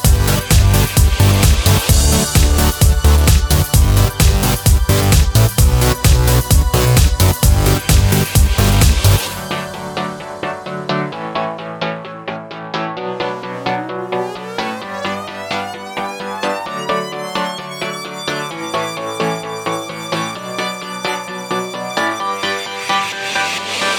no Backing Vocals Pop (2010s) 3:23 Buy £1.50